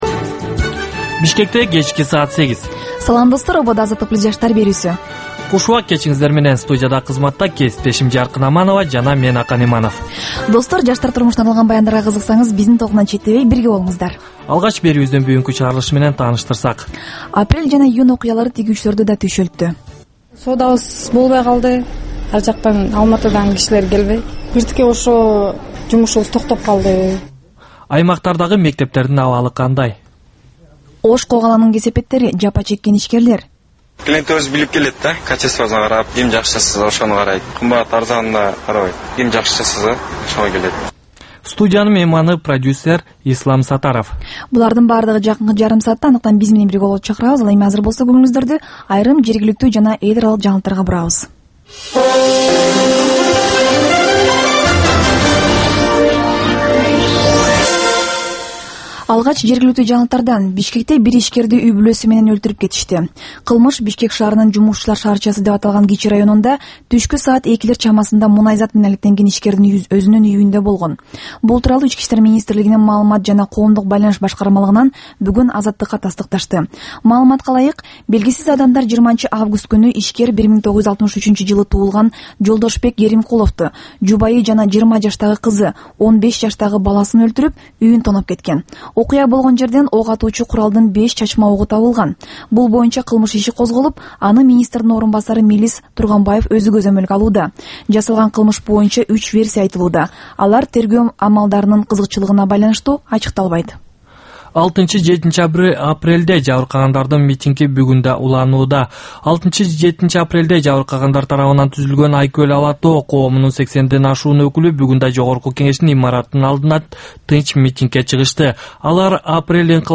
Бул жаштарга арналган кечки үналгы берүү жергиликтүү жана эл аралык кабарлардын чакан топтому, ар кыл репортаж, сереп, маек, маданий, спорттук, социалдык баян, тегерек үстөл четиндеги баарлашуу жана башка кыргызстандык жаштардын көйгөйү чагылдырылган берүүлөрдөн турат. "Азаттык үналгысынын" бул жаштар берүүсү Бишкек убакыты боюнча саат 20:00дан 20:30га чейин обого түз чыгат.